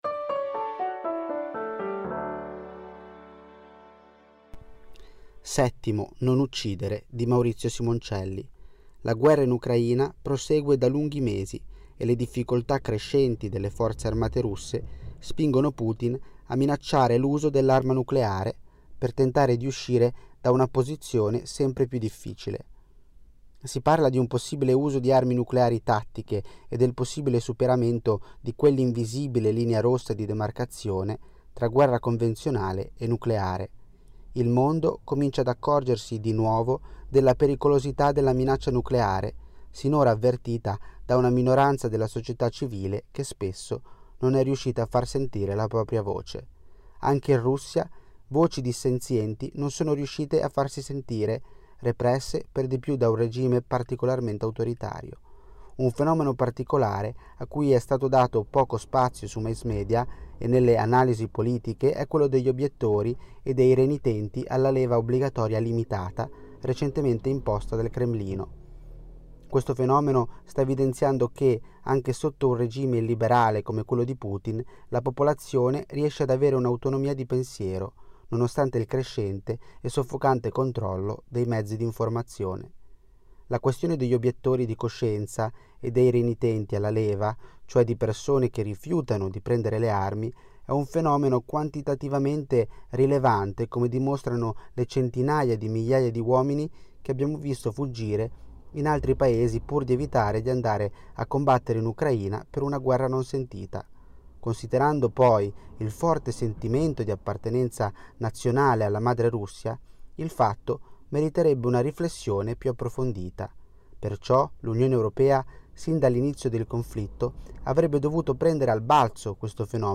Al microfono, i nostri redattori e i nostri collaboratori.
Ecco i 10 articoli letti per voi dalla rivista di novembre.